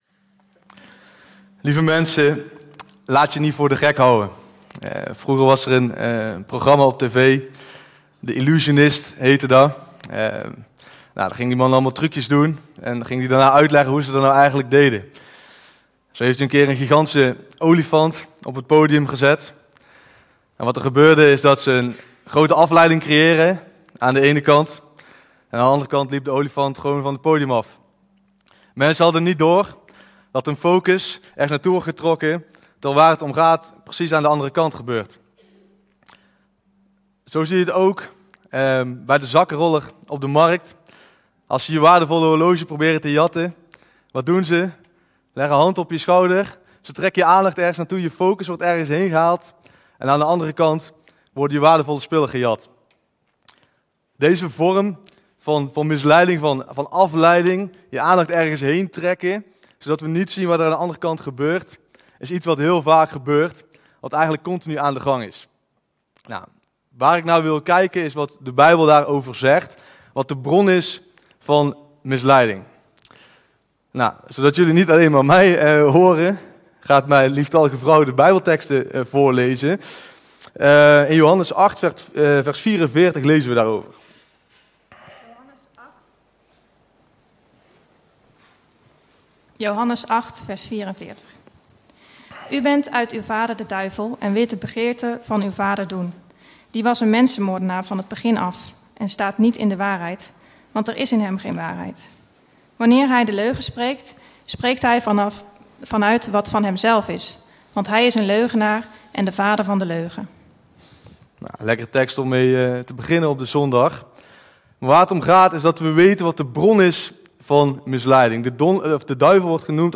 Toespraak 16 augustus: Laat je niet voor de gek houden; volg Gods verhaal... - De Bron Eindhoven